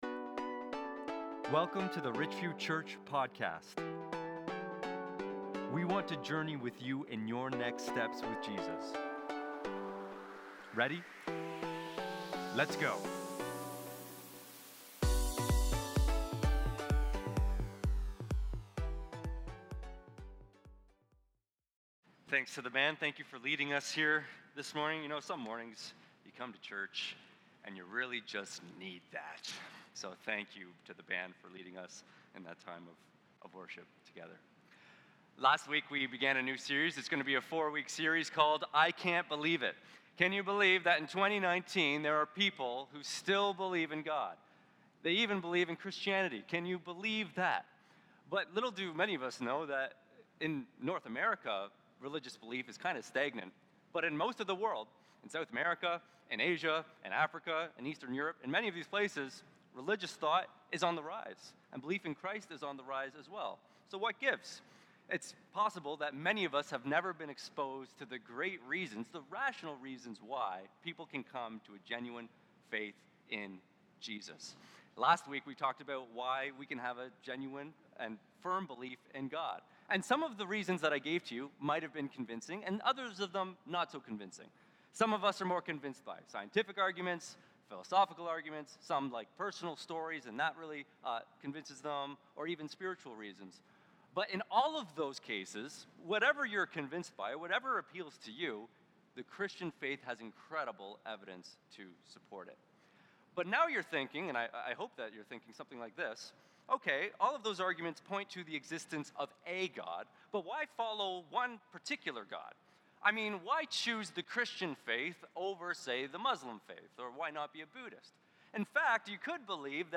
Sermon Slides